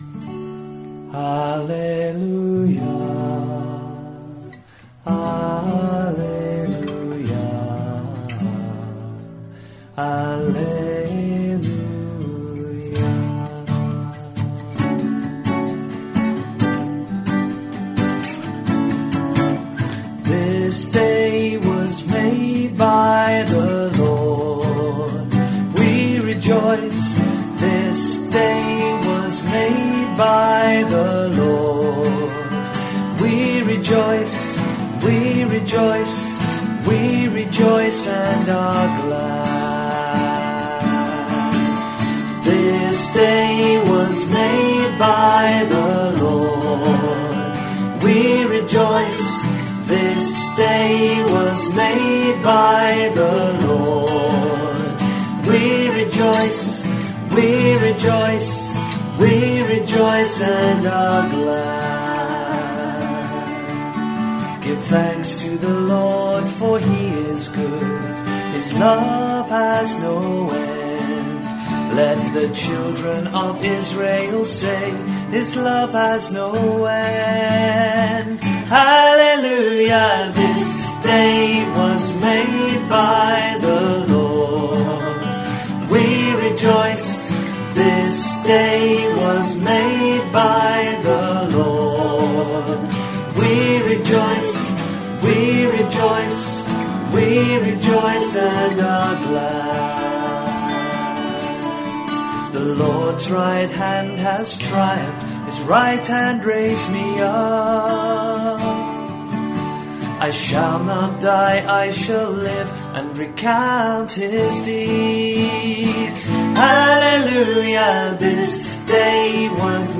Responsorial Psalm for the Easter Sunday [Year A, B, C].